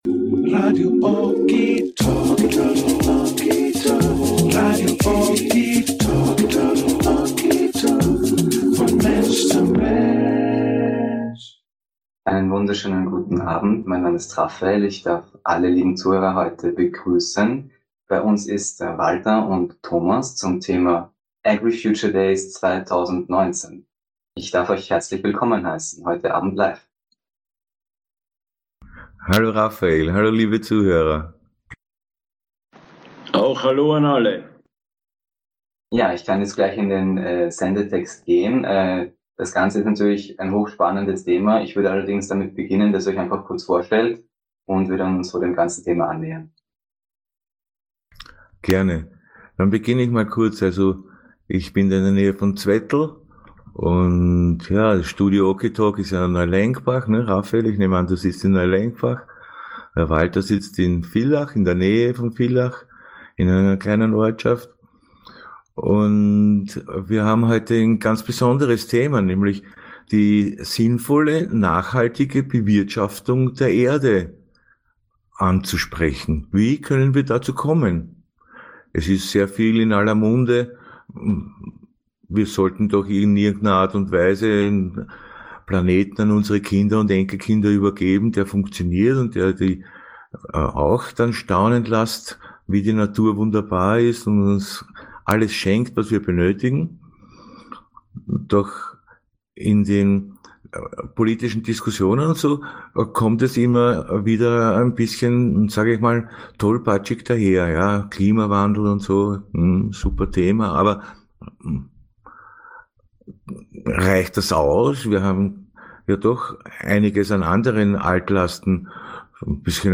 Aufzeichnungen von Livesendungen bei Radio Okitalk